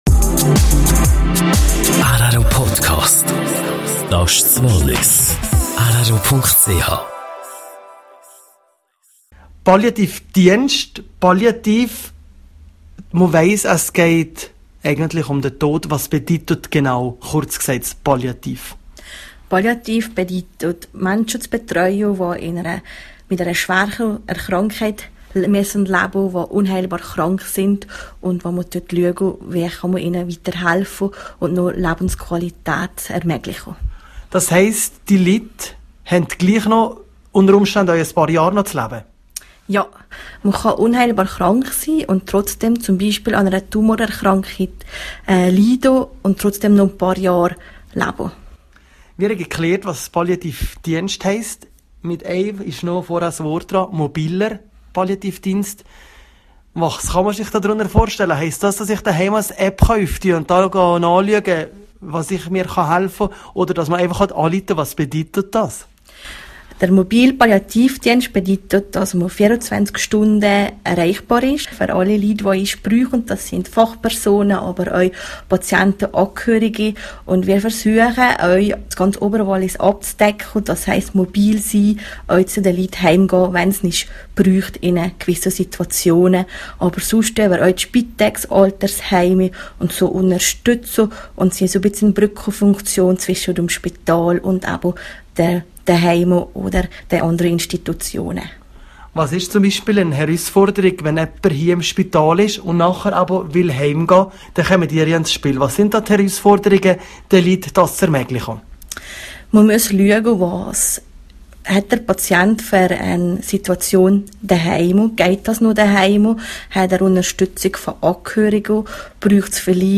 Das ganze Interview